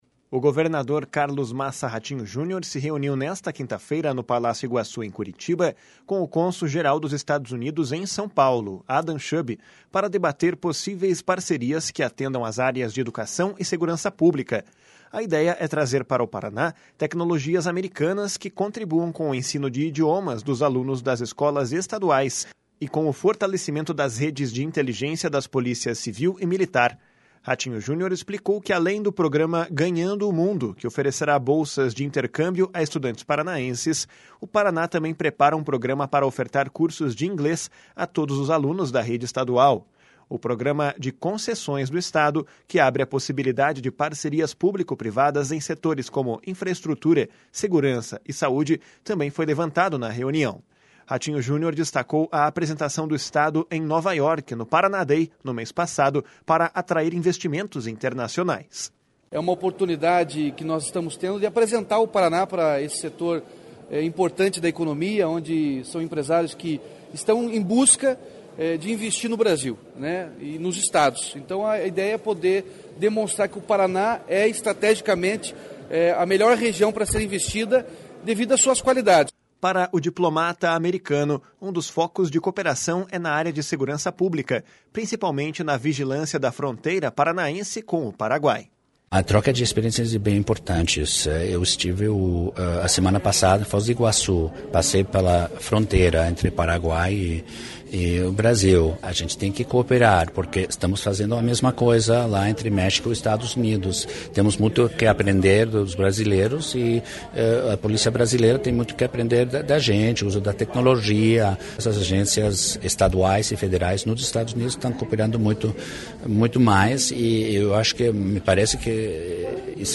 // SONORA RATINHO JUNIOR //
// SONORA ADAM SHUB //